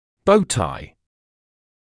[‘bəuˌtaɪ][‘боуˌтай]галстук-бабочка; имеющий форму галстука-бабочки
bow-tie.mp3